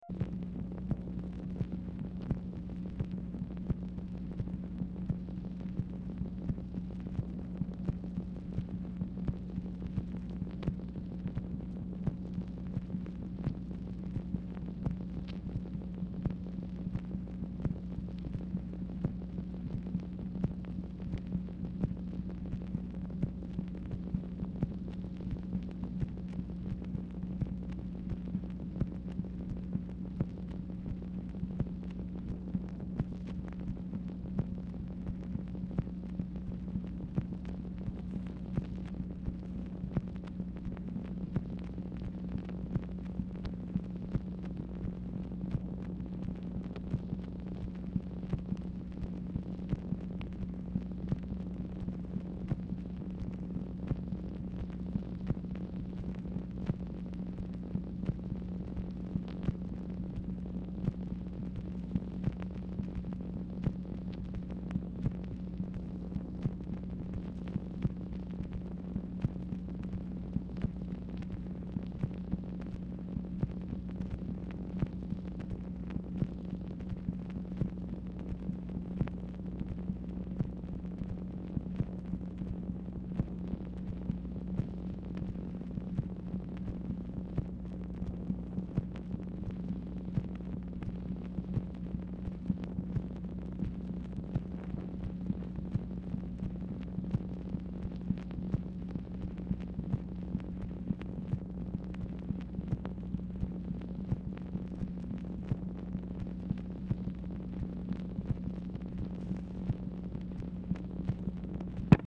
Telephone conversation # 9018, sound recording, MACHINE NOISE, 10/5/1965, time unknown | Discover LBJ
Format Dictation belt